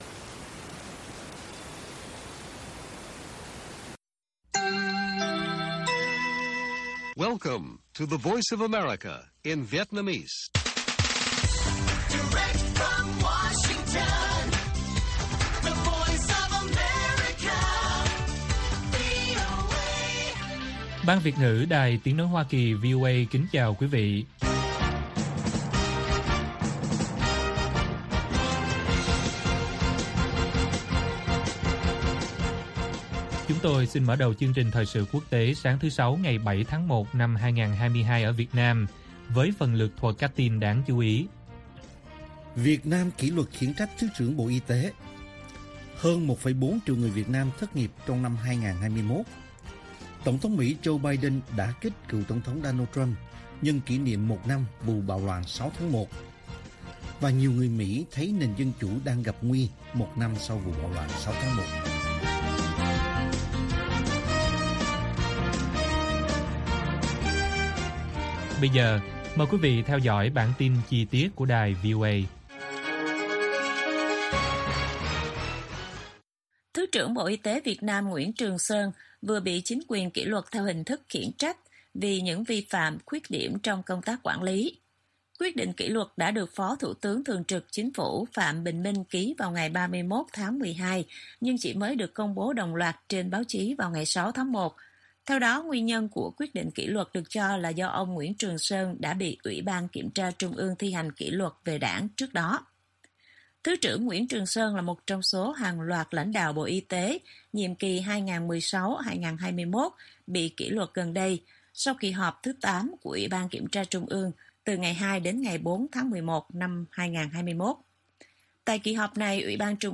Bản tin VOA ngày 7/1/2022